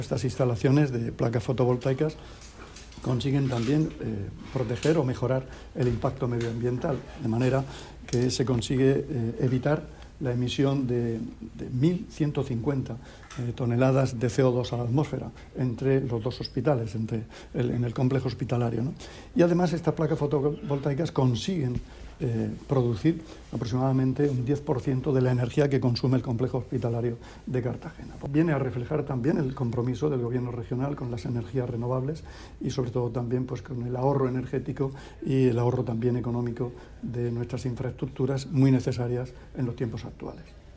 Sonido/ Declaraciones del consejero de Salud, Juan José Pedreño, sobre la instalación fotovoltaica en el Complejo Hospitalario de Cartagena [mp3].